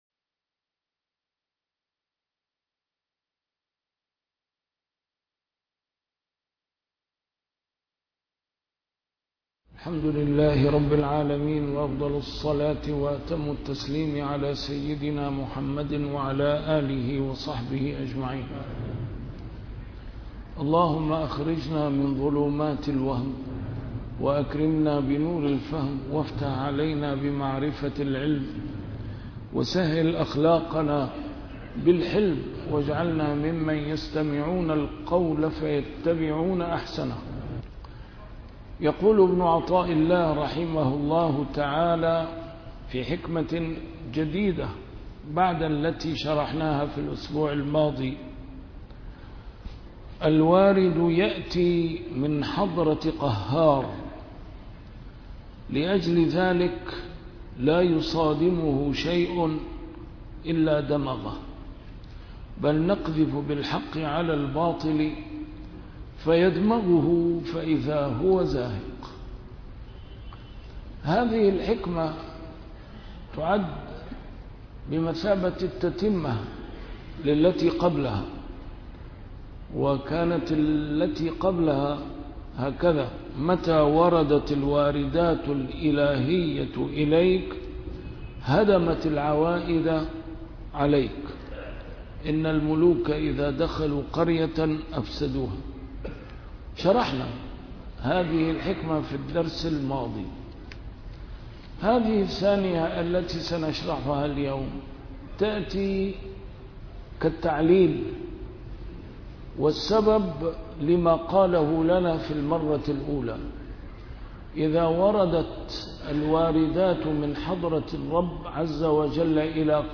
شرح الحكم العطائية - A MARTYR SCHOLAR: IMAM MUHAMMAD SAEED RAMADAN AL-BOUTI - الدروس العلمية - علم السلوك والتزكية - الدرس رقم 238 شرح الحكمة رقم 216